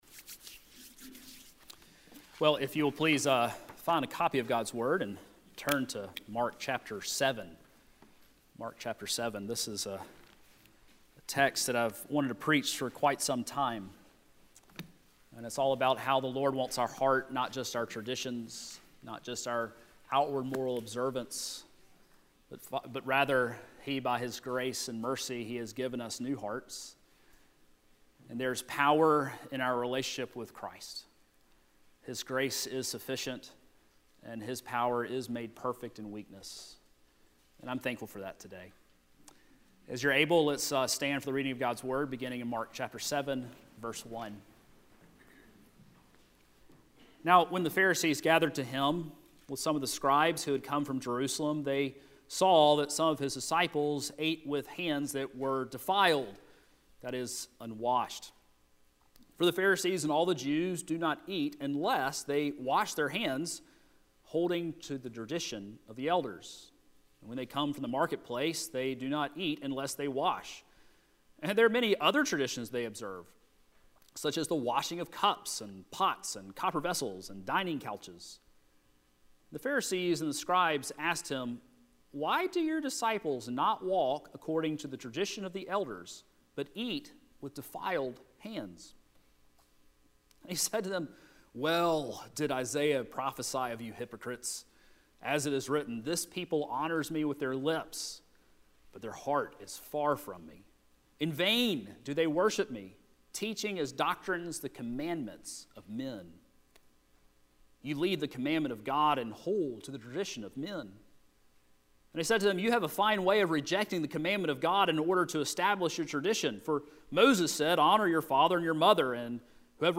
Sermons from the pulpit of First Presbyterian Church (PCA) of Brewton, AL